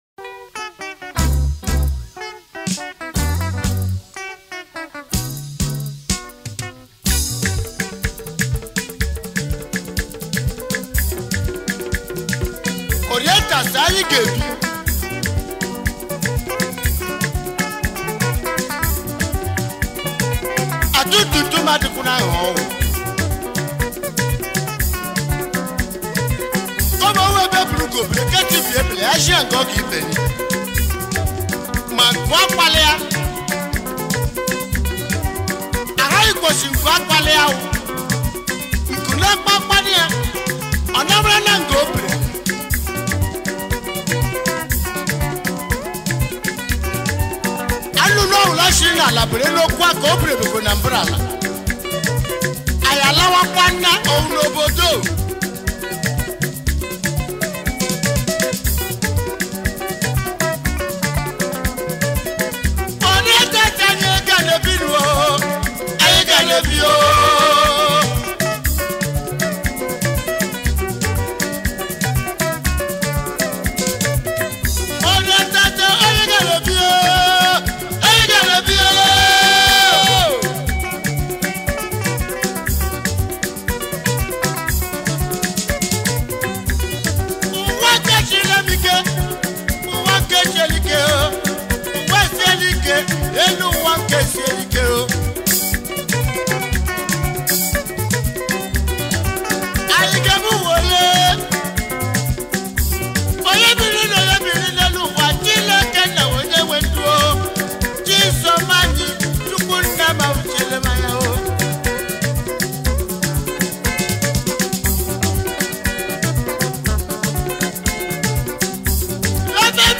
high life band